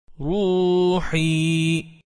1. Tabii Med (Medd-i Tabii):
Bir harfin önüne harf-i med gelirse, o harf uzatılarak okunur.
Tabii med hareke uzunluğunun iki katı uzatılır.